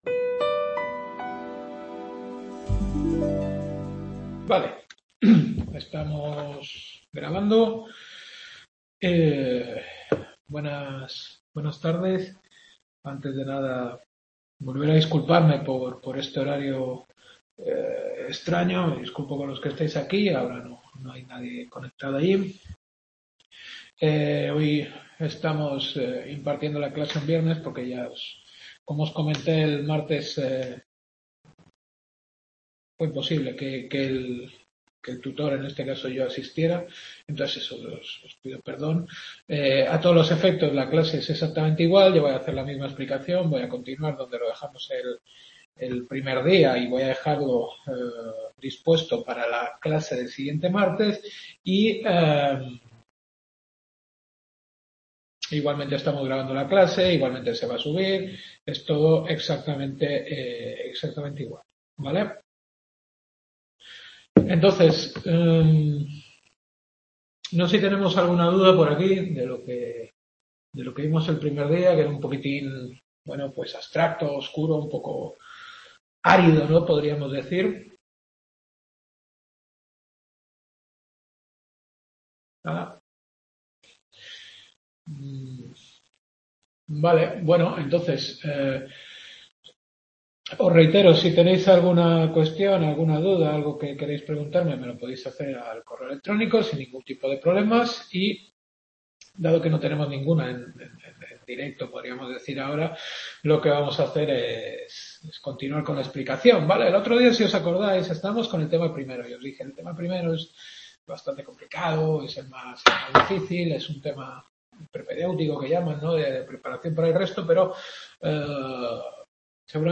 Filosofía del Derecho. Segunda Clase.